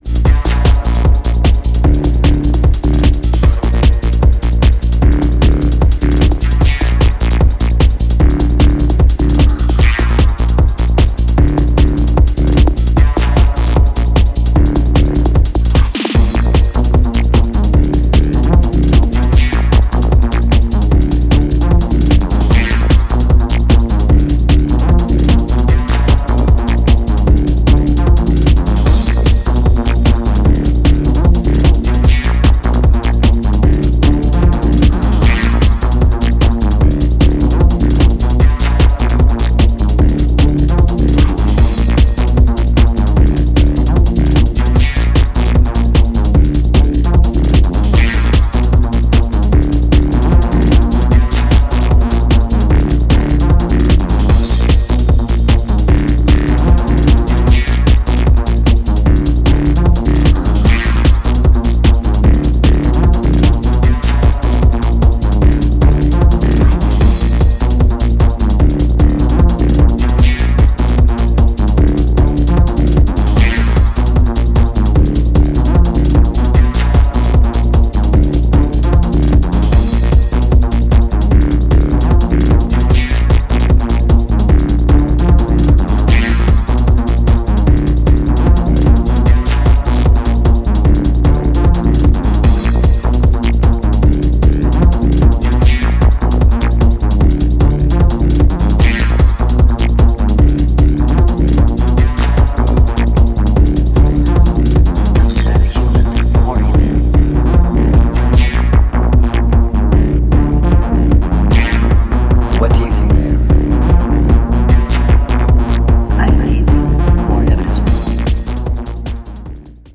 Downtempo , Techno